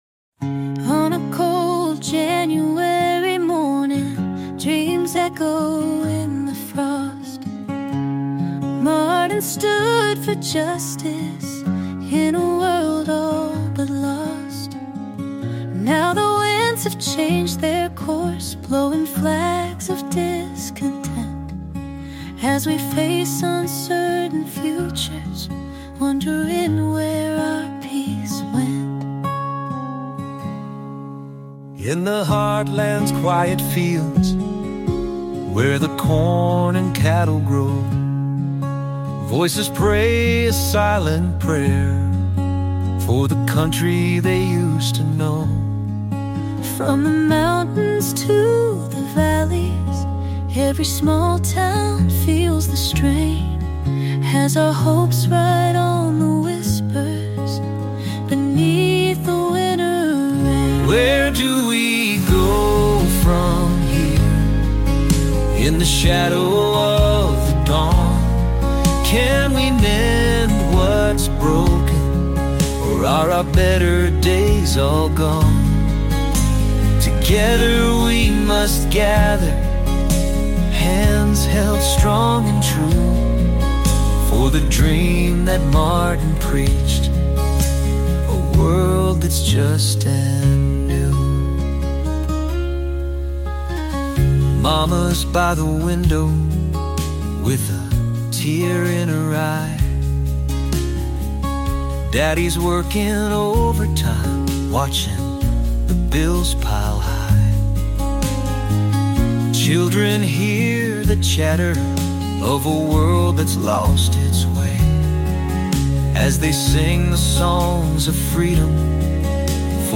(country/blues)